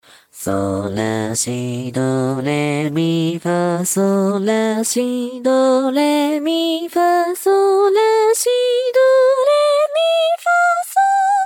性別：女
VOICEBANK
收錄音階：G3~A3   B3~C4  F4~G4  B4~C5